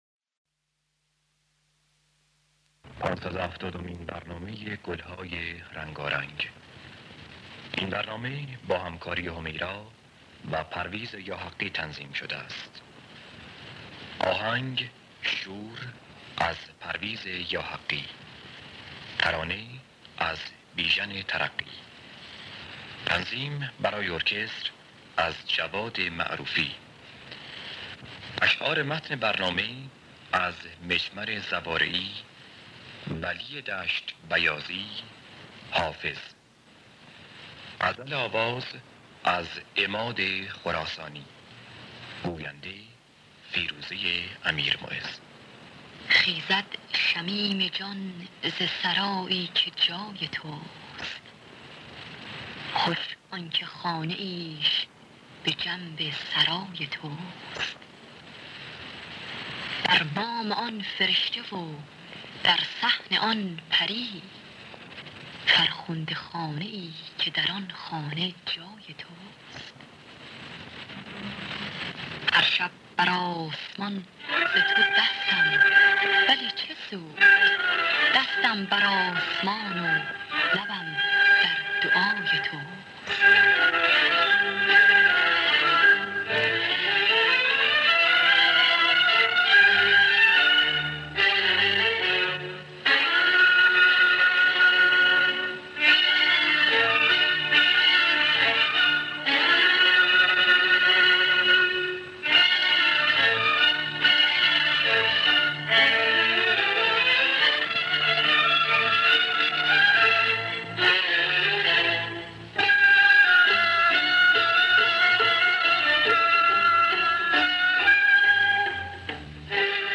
آرشیو کامل برنامه‌های رادیو ایران با کیفیت بالا.